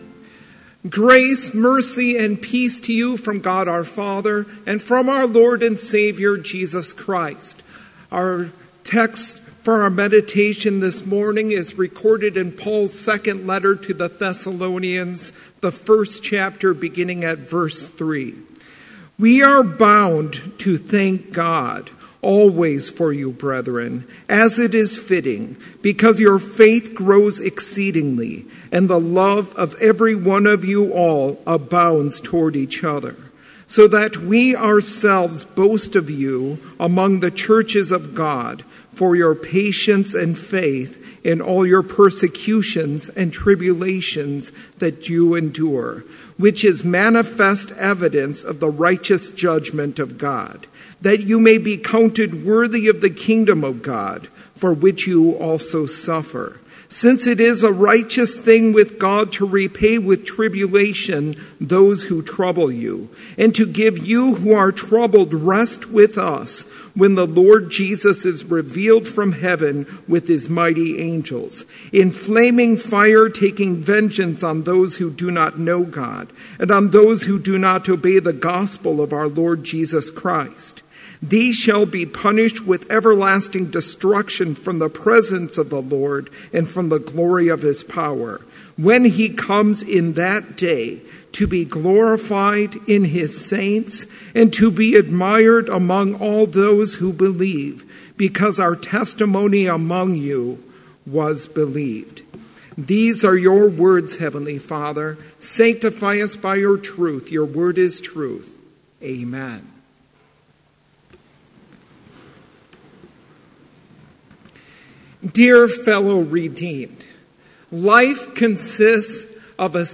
Complete service audio for Chapel - November 15, 2016